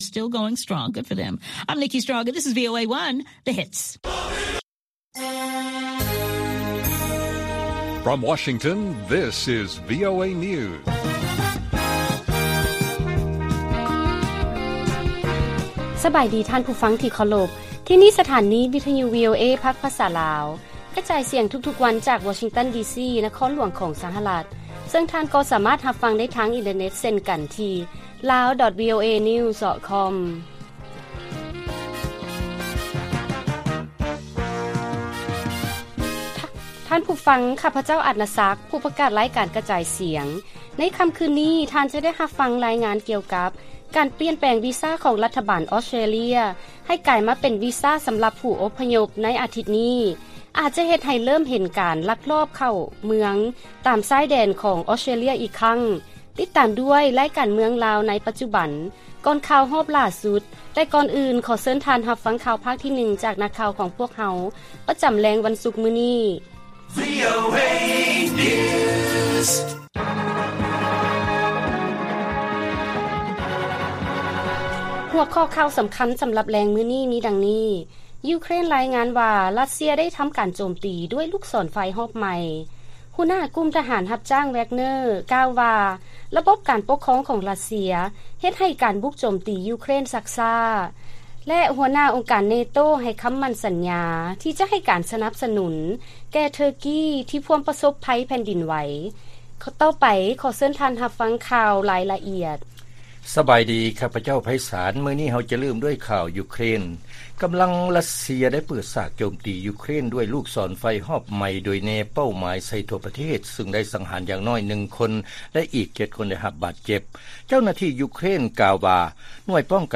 ລາຍການກະຈາຍສຽງຂອງວີໂອເອ ລາວ: ຢູເຄຣນ ລາຍງານວ່າ ຣັດເຊຍທຳການໂຈມຕີດ້ວຍລູກສອນໄຟຮອບໃໝ່